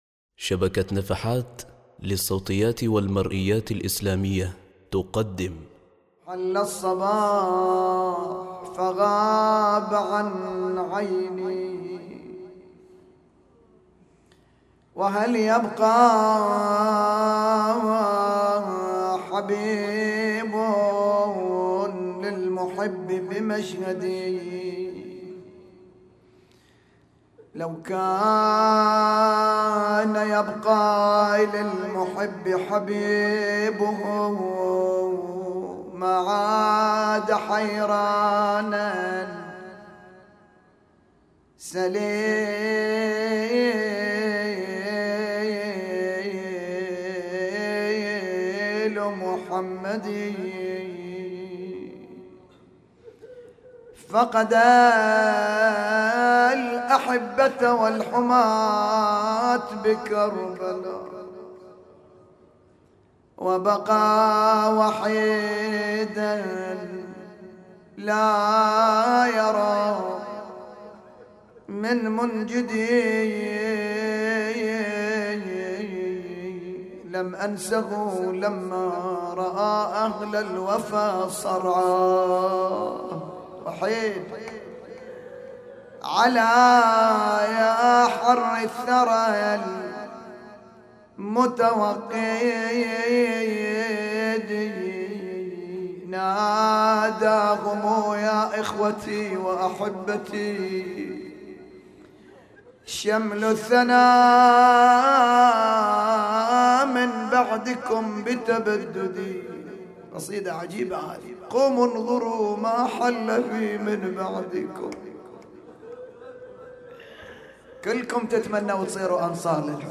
نعي ليلة 6 محرم 1439هـ |